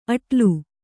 ♪ aṭlu